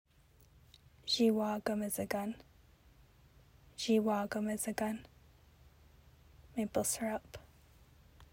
Anishinaabemowin pronunciation: "zhee-wah-gum-izi-gun"